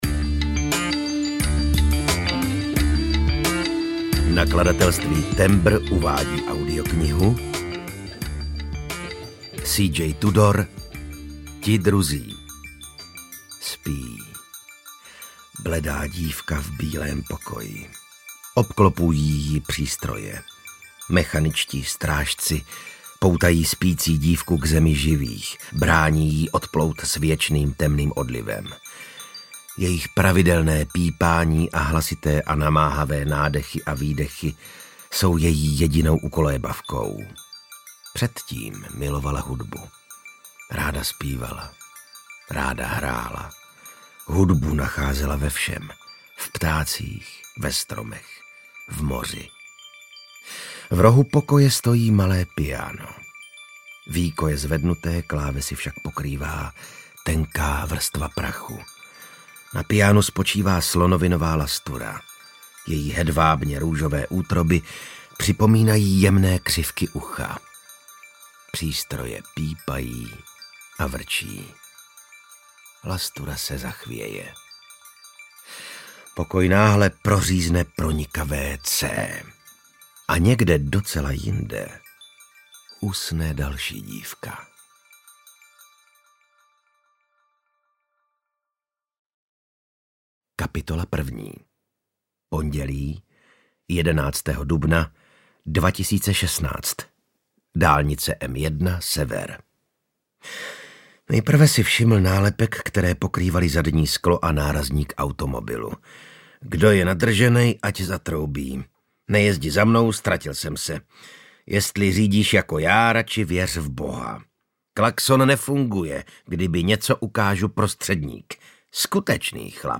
Ti druzí audiokniha
Ukázka z knihy